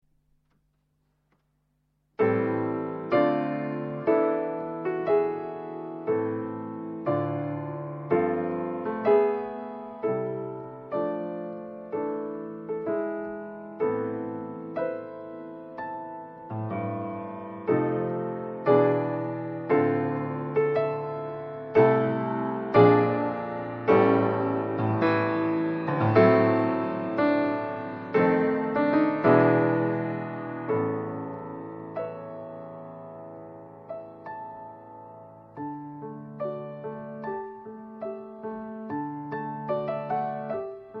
piano
fluit